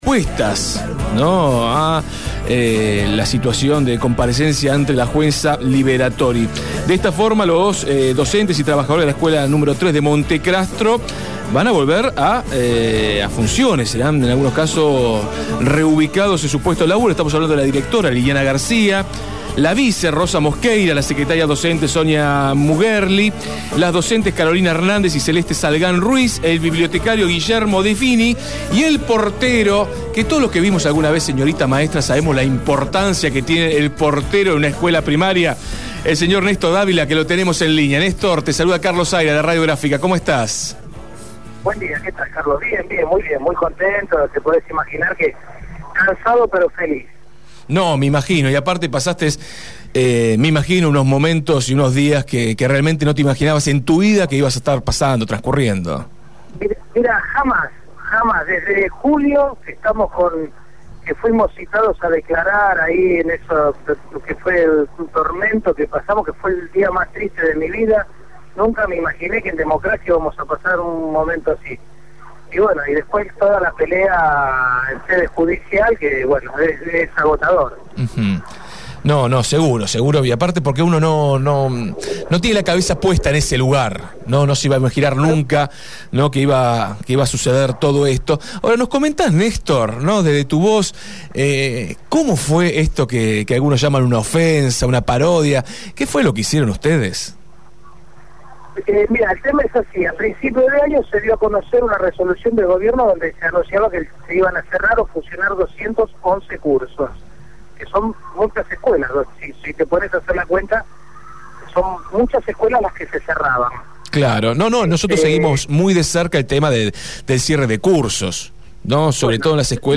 dialogó con Desde el Barrio.